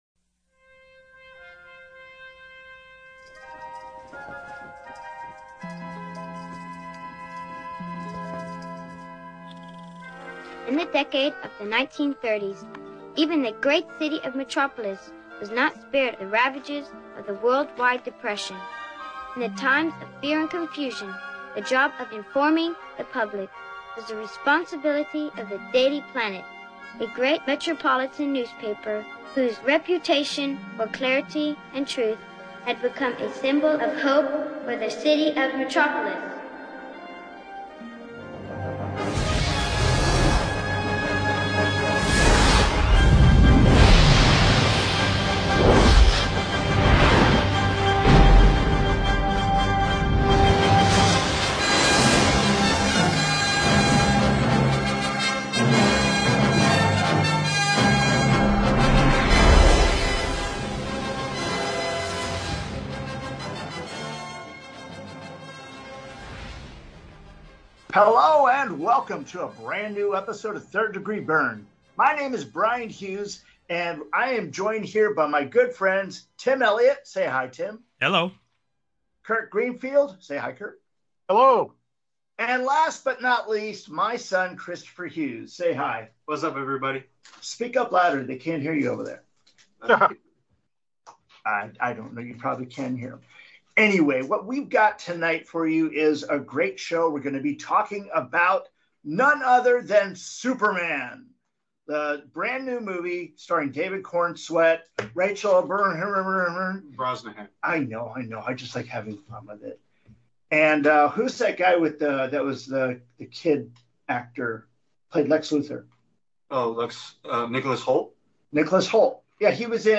3rd Degree Byrne Presents a Special Discussion on Superman – Two True Freaks